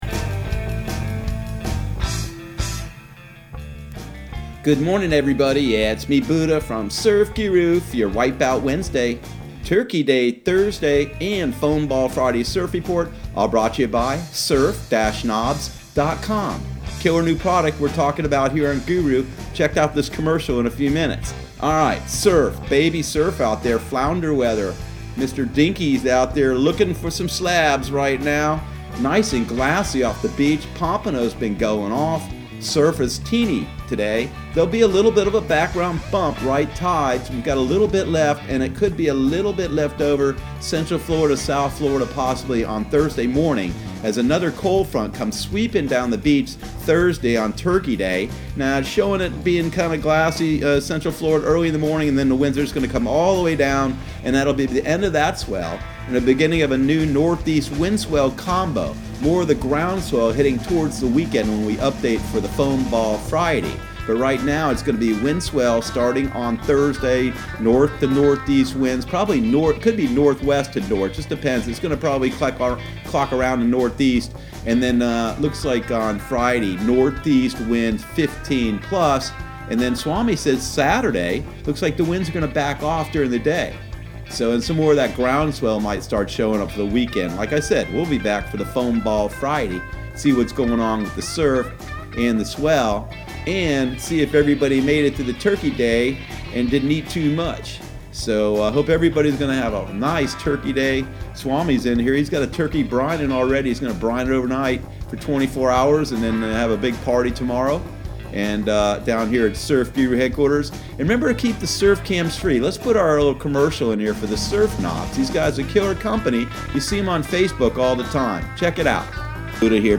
Surf Guru Surf Report and Forecast 11/27/2019 Audio surf report and surf forecast on November 27 for Central Florida and the Southeast.